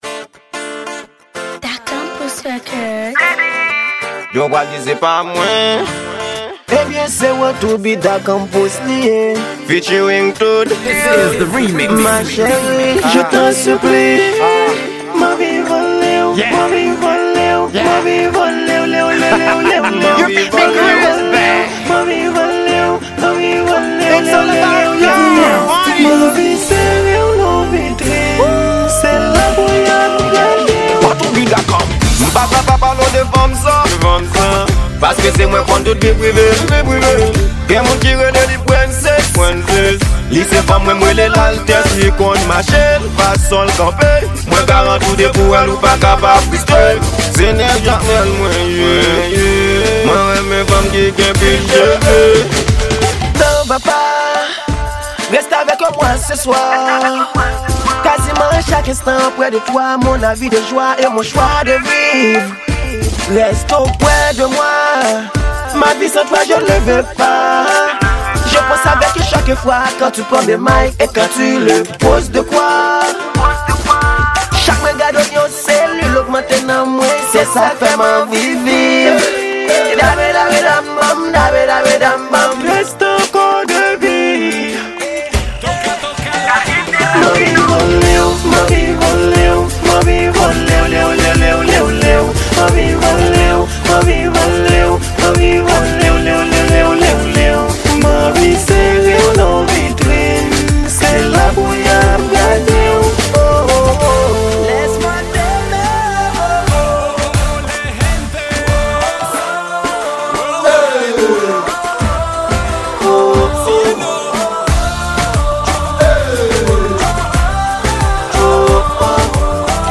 Genre: Wold.